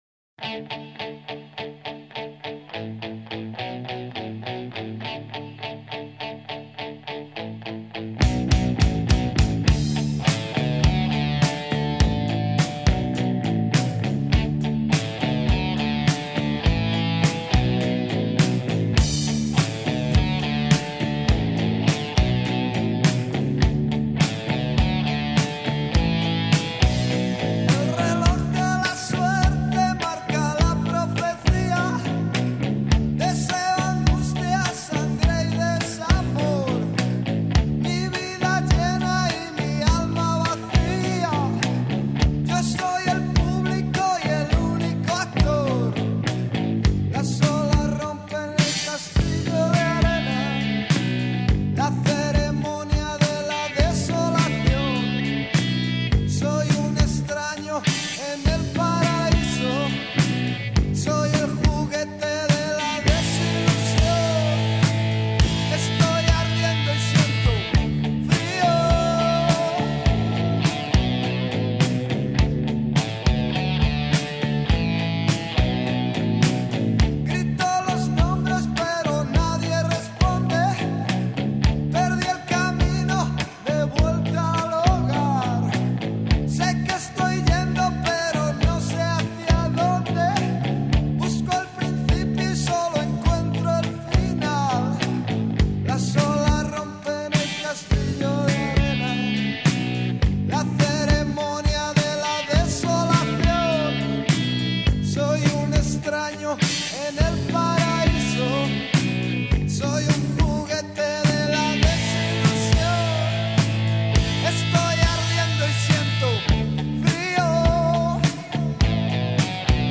Alarma Frio.mp3